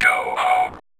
VVE1 Vocoder Phrases
VVE1 Vocoder Phrases 23.wav